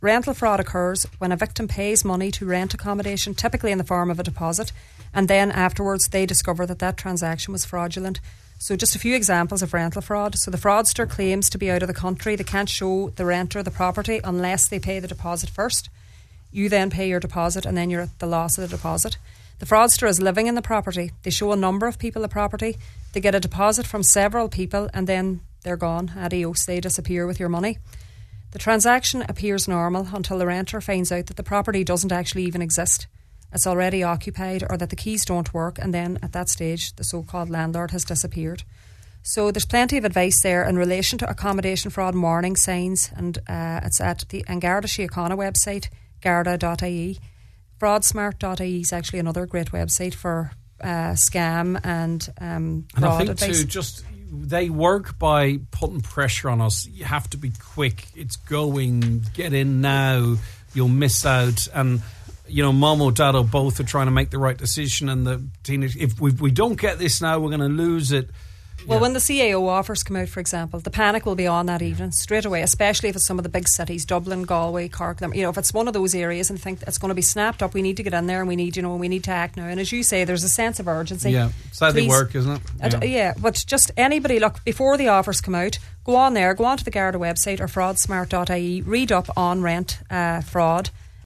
gave this advice on this morning’s Nine ‘Til Noon Show: